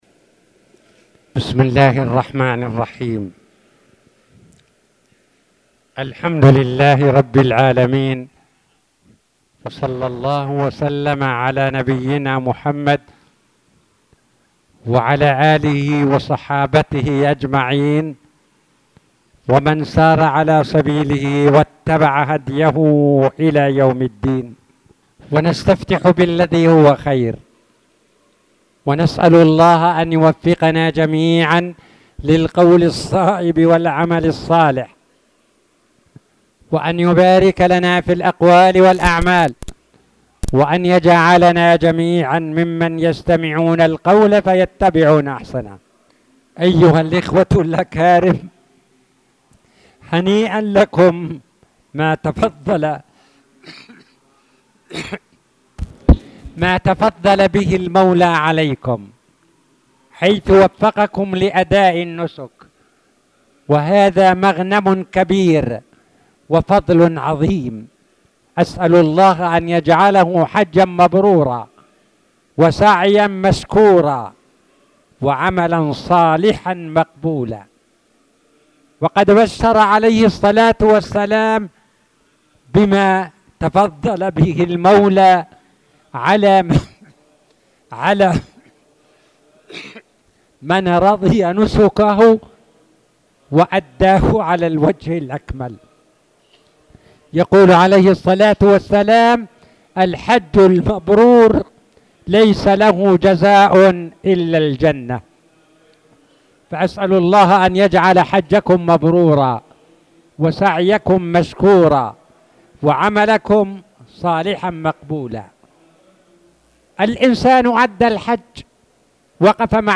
تاريخ النشر ١٧ ذو الحجة ١٤٣٧ هـ المكان: المسجد الحرام الشيخ